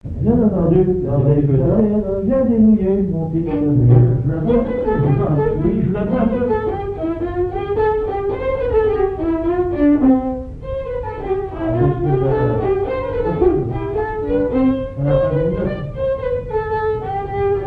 Mémoires et Patrimoines vivants - RaddO est une base de données d'archives iconographiques et sonores.
danse : quadrille : avant-deux
Airs à danser aux violons
Pièce musicale inédite